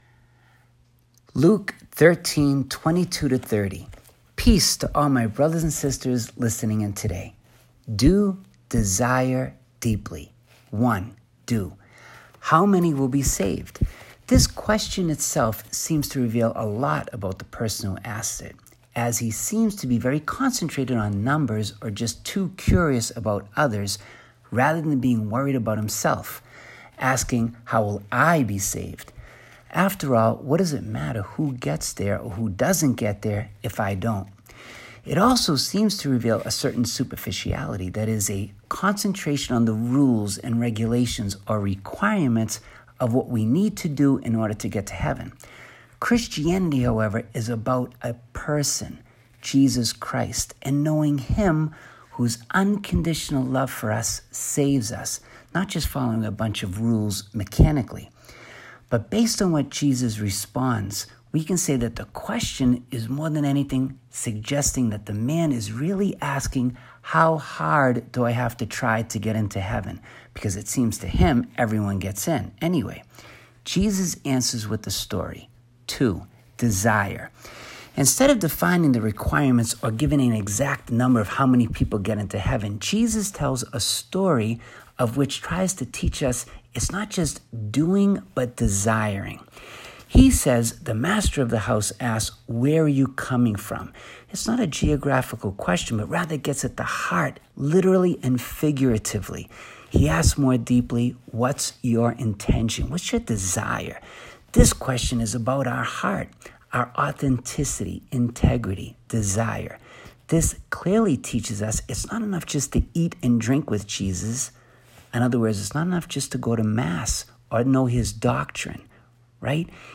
Daily Meditation